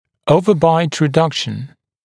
[‘əuvəbaɪt rɪ’dʌkʃn][‘оувэбайт ри’дакшн]уменьшение вертикального (резцового) перекрытия